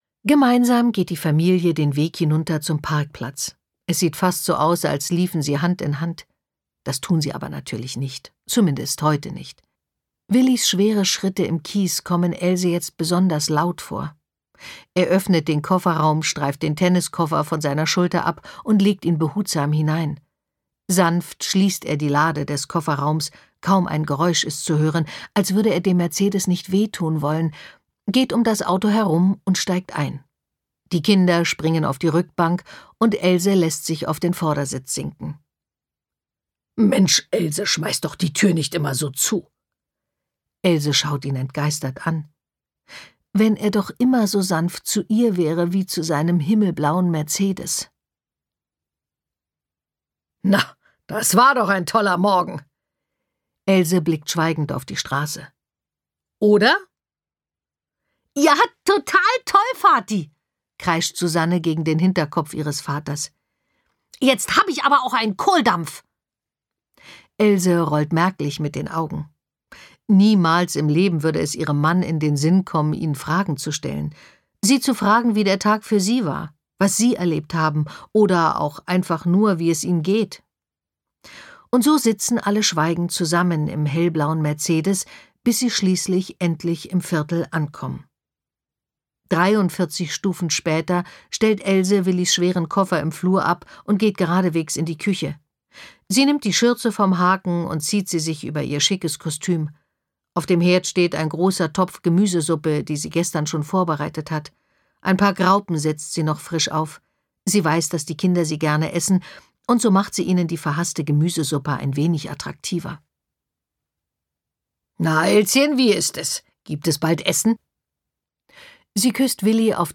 Jasna Fritzi Bauer (Sprecher)